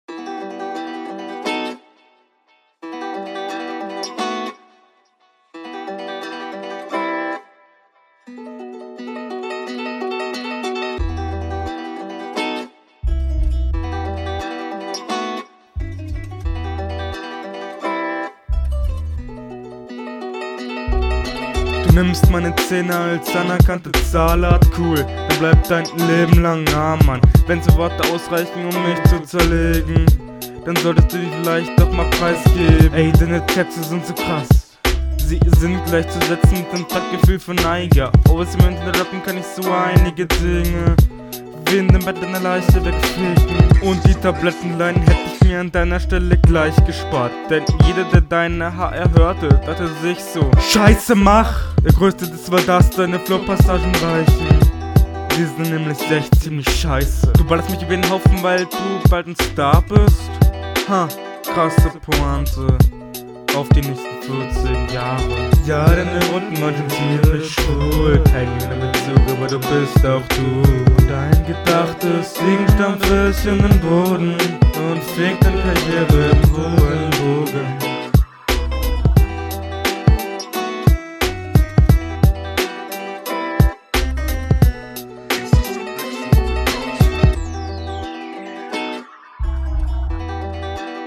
Rapmäßig kommt das leider nicht ganz so mächtig wie die Hinrunde.
schwer verständlich , du bist deinem gegner in allem belangen unterlegen, teilwiese auch offbeat, klarer …